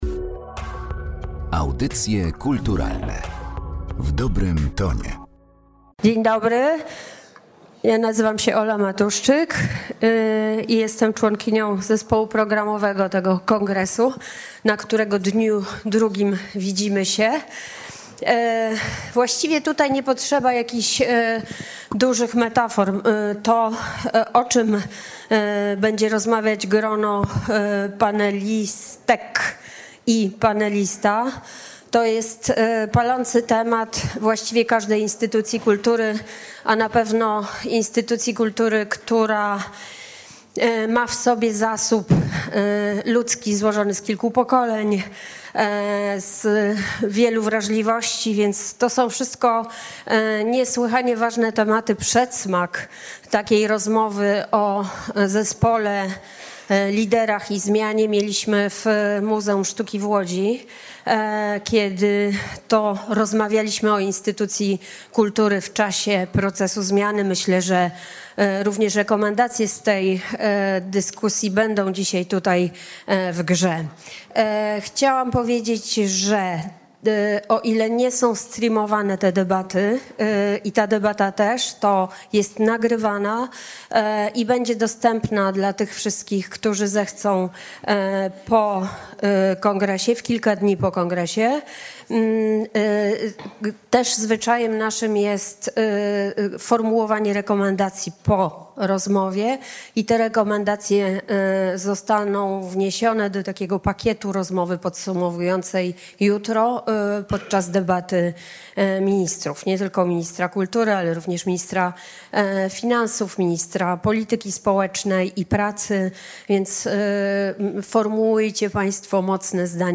Zapraszamy do wysłuchania zapisu jednej z debat przeprowadzonych podczas współKongresu Kultury, który odbył się w Warszawie e dniach 7-9 listopada.
Debata_liderstwo.mp3